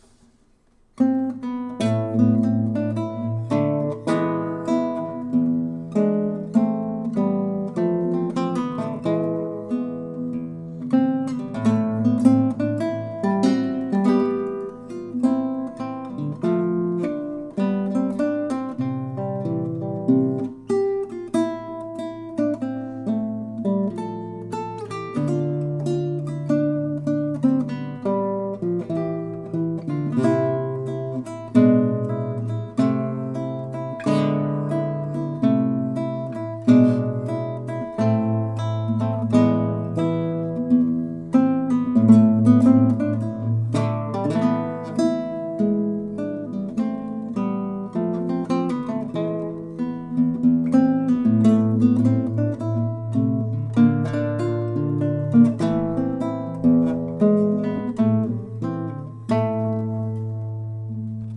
Live Classical Guitar performances